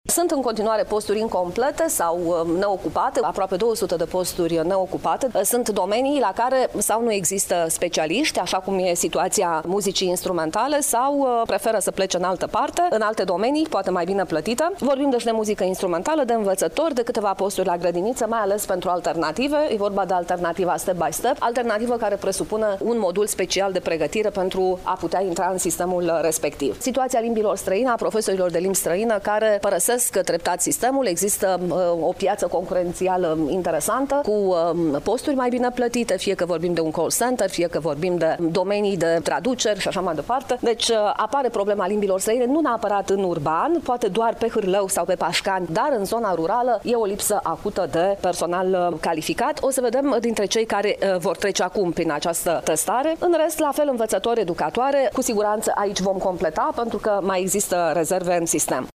Șefa Inspectorului Școlar general Iași, Camelia Gavrilă, a mai spus că în momentul de față nu există specialiști care să completeze locurile pentru specialitatea muzică instrumentală și sunt foarte puține solicitări pentru învățători.